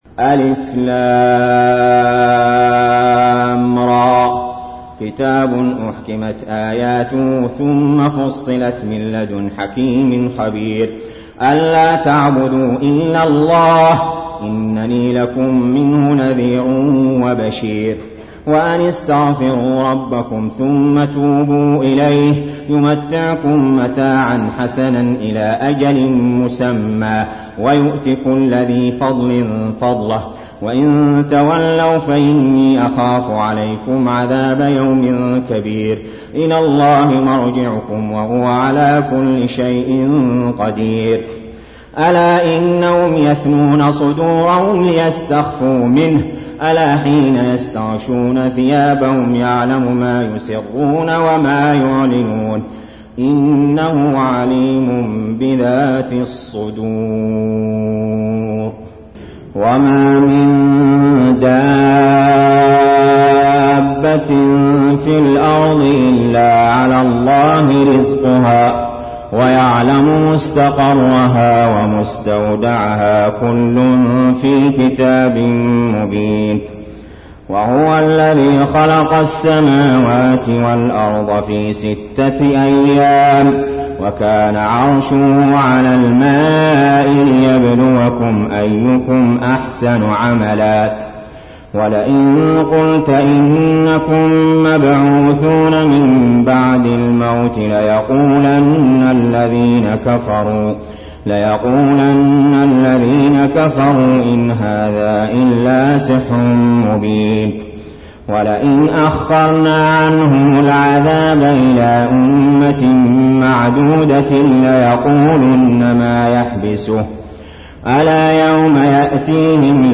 المكان: المسجد الحرام الشيخ: علي جابر رحمه الله علي جابر رحمه الله هود